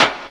Snare (16).wav